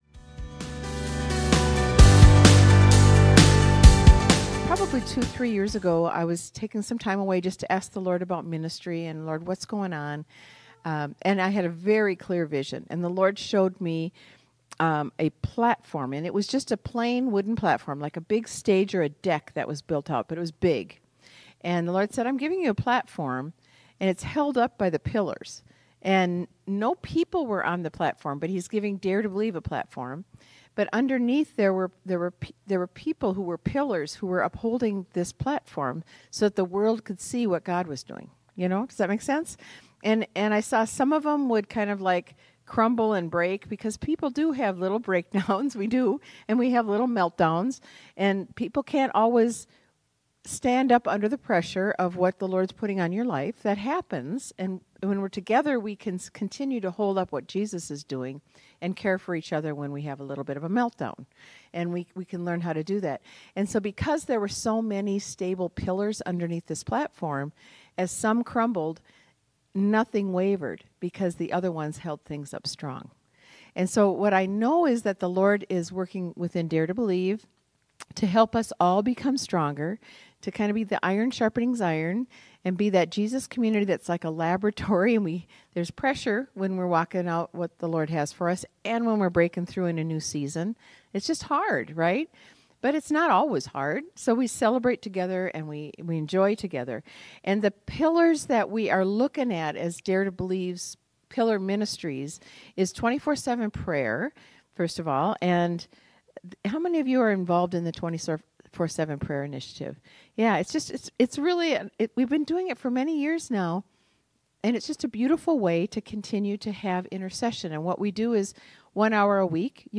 In this episode, tune in as she shares from her heart and casts vision to some of our staff and volunteers at a gathering at the beginning of January 2026. We’re dreaming with God about what’s ahead, and wanted to specifically share about the foundational pillars of Dare to Believe Ministries so you can know what the ministry is all about.